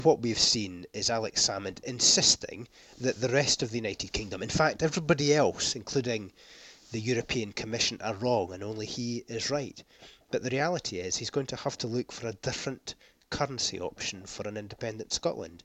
That's the message from Scottish LibDem leader Willie Rennie.
He's telling us the First Minister is the last man standing in defence of 'unworkable' currency plans.